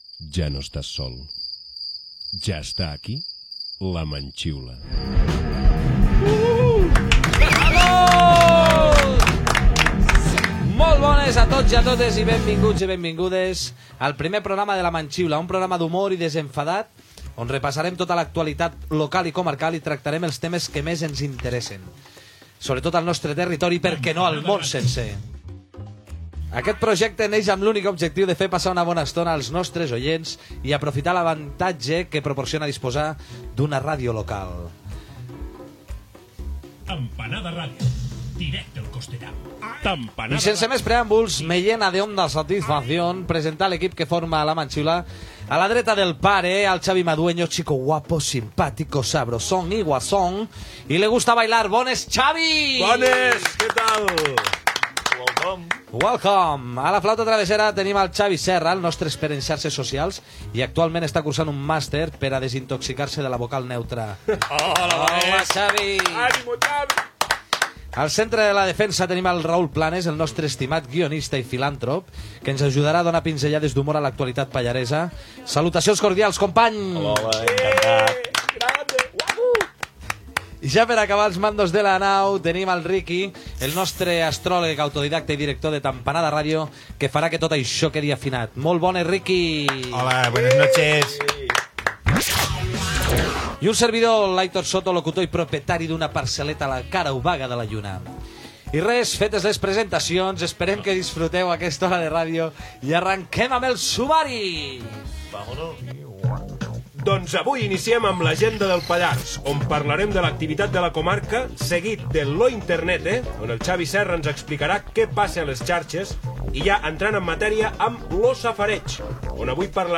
1831fca09644fe2673d488bb2b857a1860dcaa76.mp3 Títol Tampanada Ràdio Emissora Tampanada Ràdio Titularitat Tercer sector Tercer sector Altres Nom programa La manxiula Descripció Presentació del programa i l'equip, sumari. "Lo Pallars cultural": el carnaval i calendari d'activitats del mes de març. "La paraula del senyor": mots pallaresos, la "manxiula". Gènere radiofònic Entreteniment